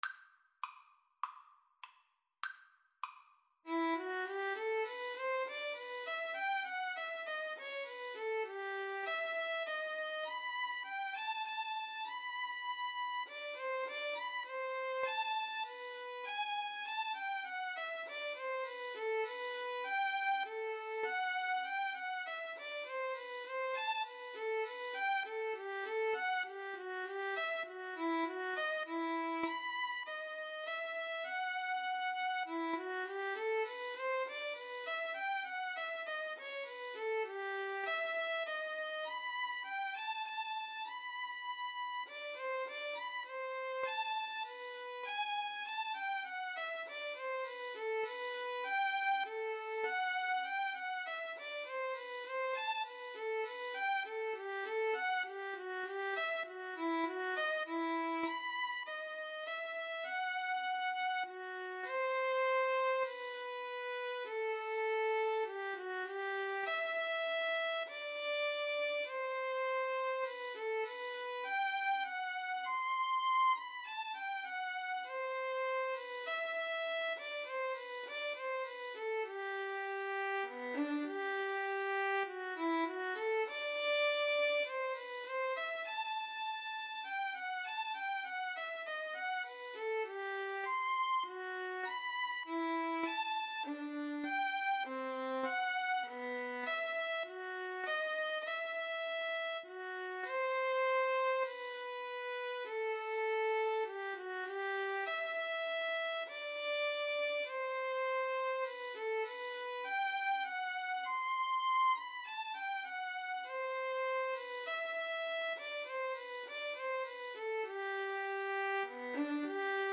2/2 (View more 2/2 Music)
Classical (View more Classical Violin-Viola Duet Music)